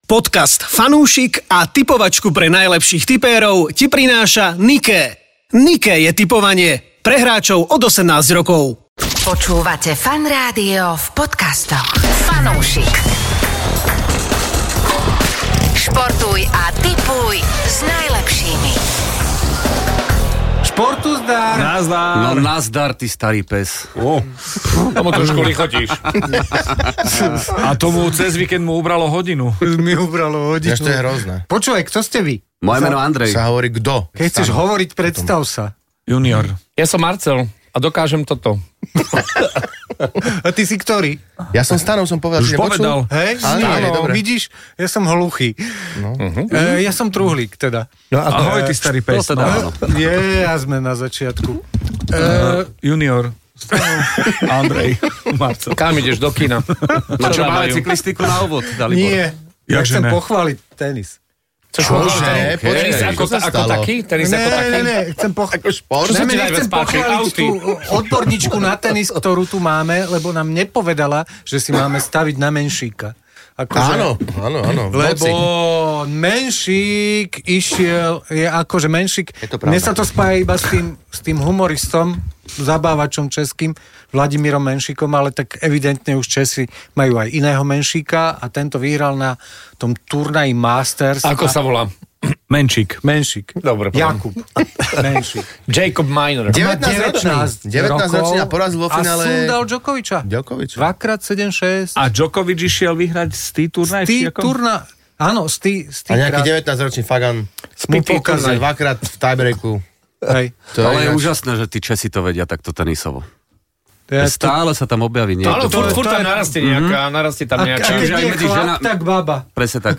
Daj si fanúšikovskú debatku o športe a tipovaní.